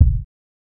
Kick (IFHY).wav